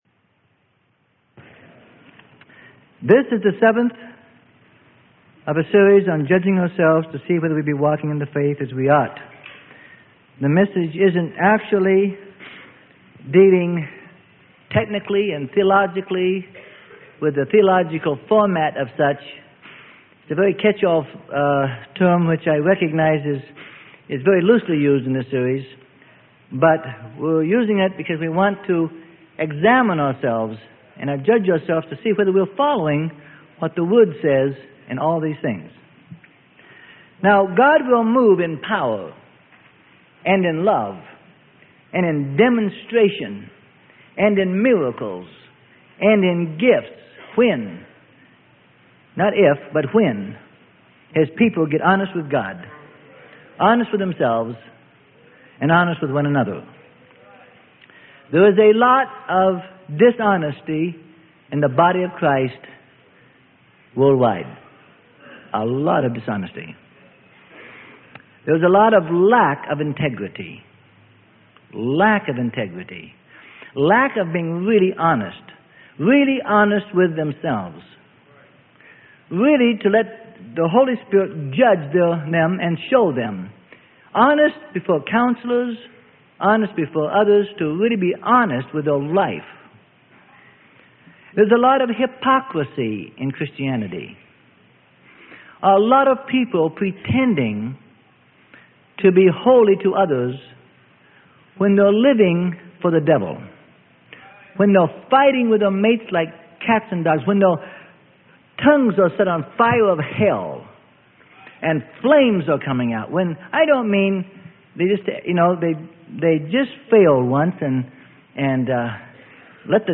Sermon: Judging Ourselves to See Whether We Be Walking in the Faith as We Ought - Part 7 - Freely Given Online Library